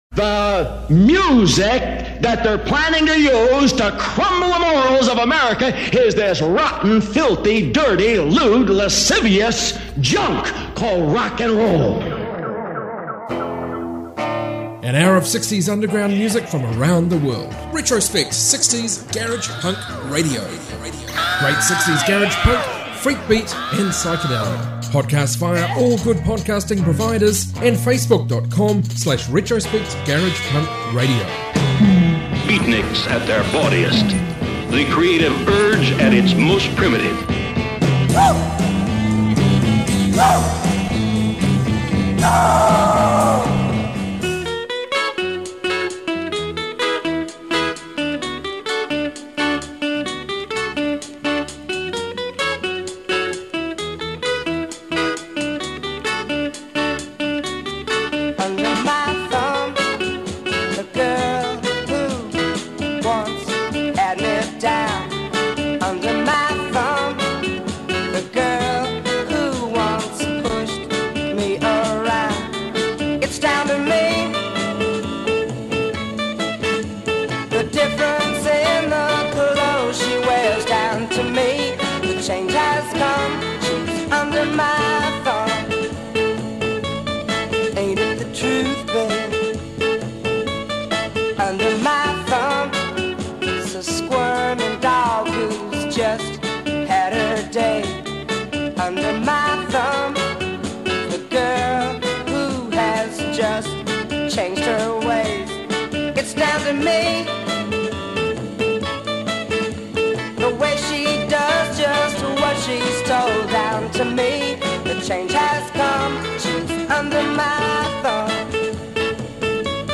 Even more way cool 60s garage music